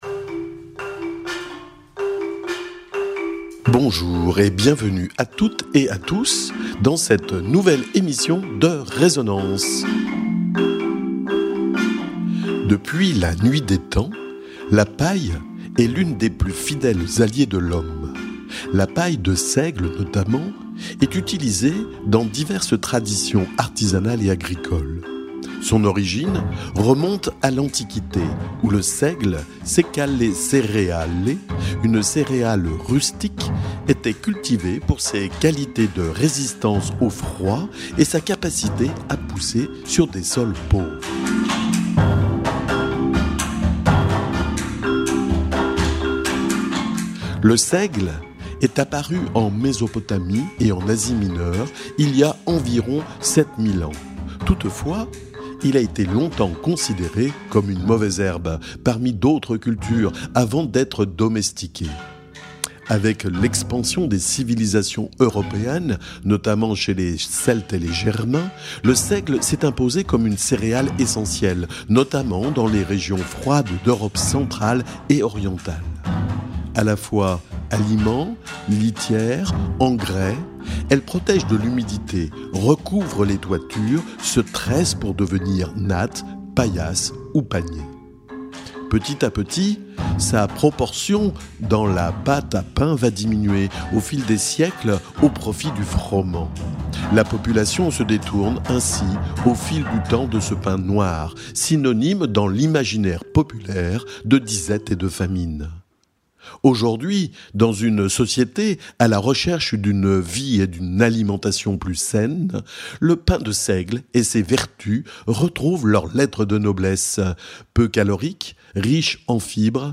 Les ouvriers et ouvrières de l’ESAT de Treffort, établissement de l’Adapei de l’Ain, nous parlent, nous racontent, le travail de préparation de la paille de seigle.
Matière naturelle, simple et rustique, la paille de seigle fascine ceux et celles qui la travaillent et notamment les ouvriers et ouvrières de l'ESAT de Treffort, établissement de l'Adapei de l'Ain, tout proche de la commune de Val Revermont. Ils ont ouvert les portes de leurs ateliers aux micros de « Résonances » et ils nous parlent, nous racontent, évoquent ce travail de préparation de la paille de seigle.